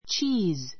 tʃíːz